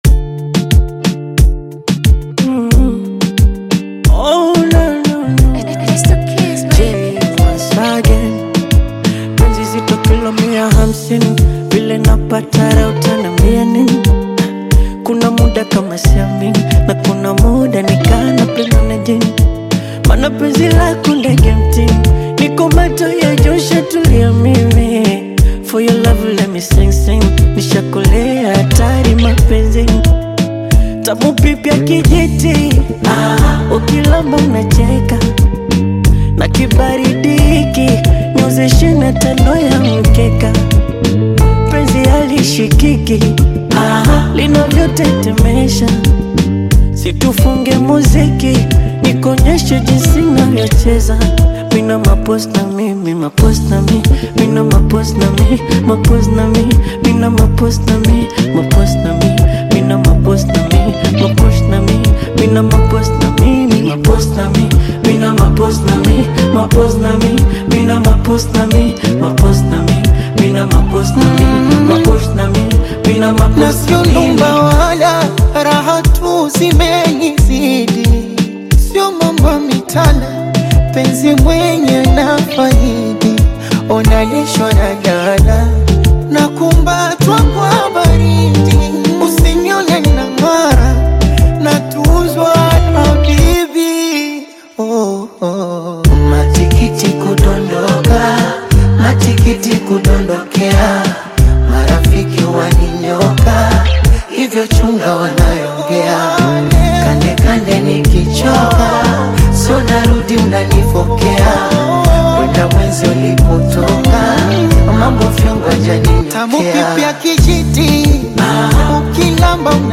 a rich, seasoned flavor